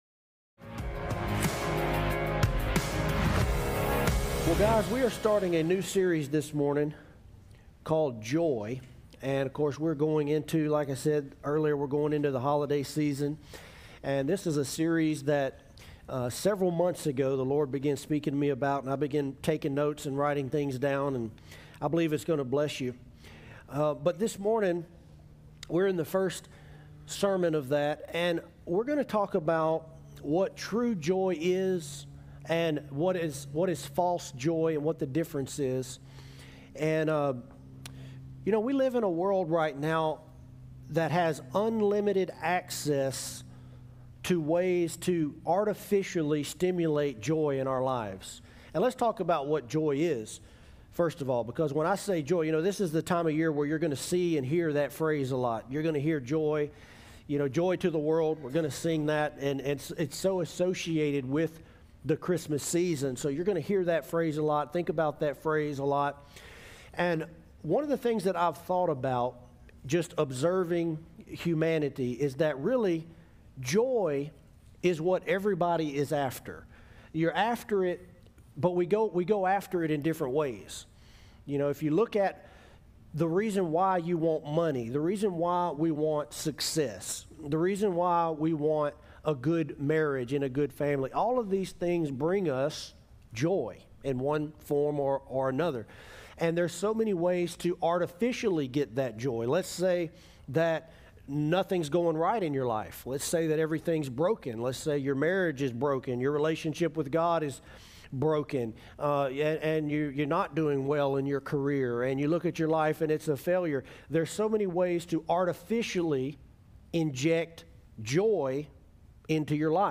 Joy Sermon Series